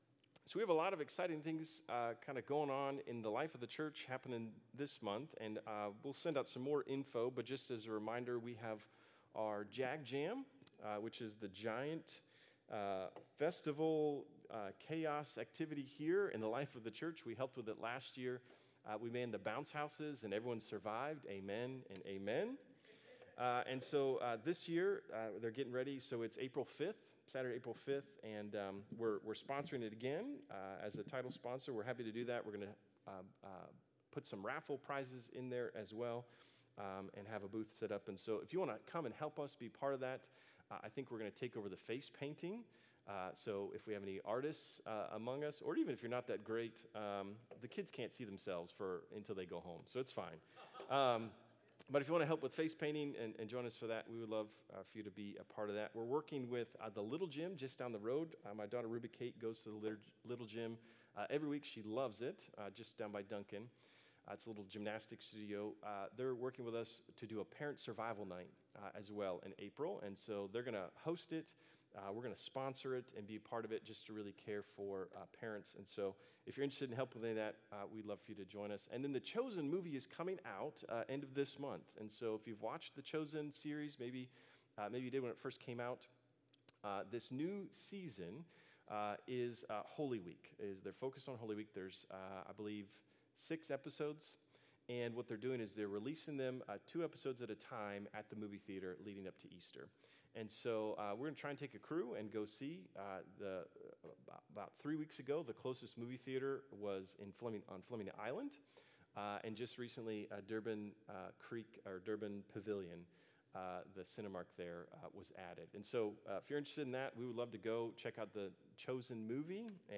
Sermons | Bridge City Church